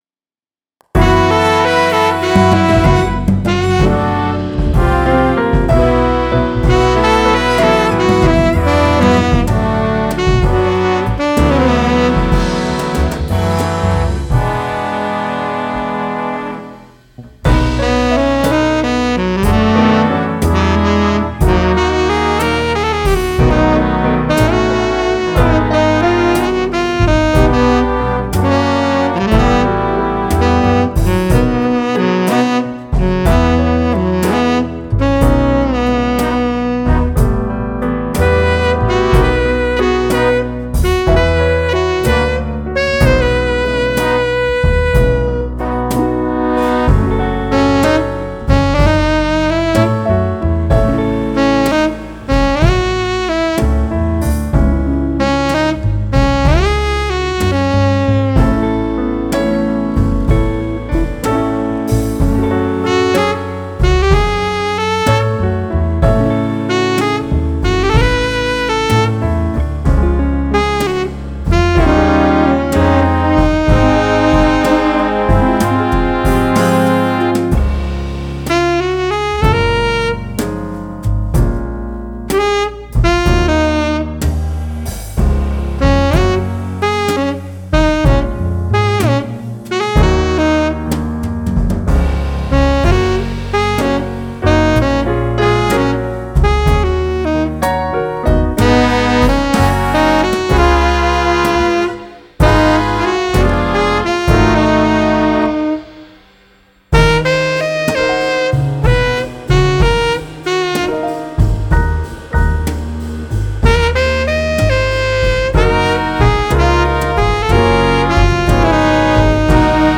"Суеверия Черного Кота" Soul&Slow Jazz Instrumental Theme (Демо)
Особо не сводил, причесал Харбалом.
Сакс видел вживую только на концертах.